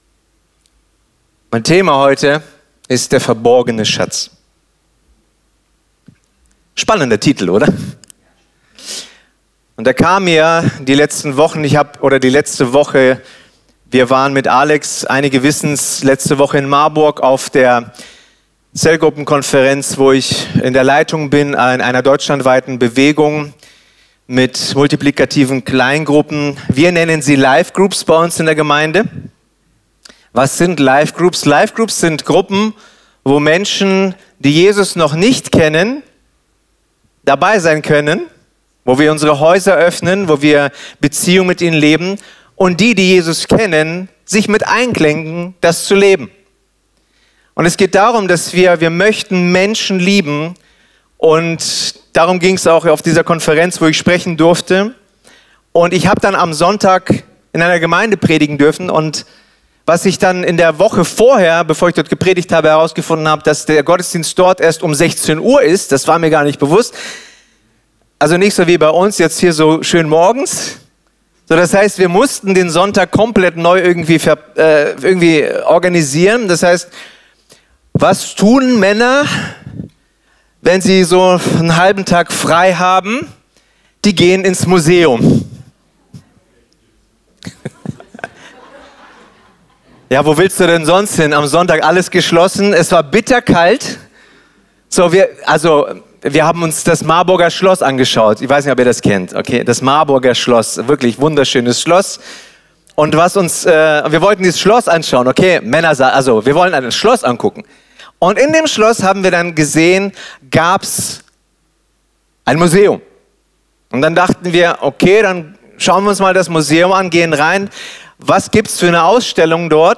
Sonntagspredigten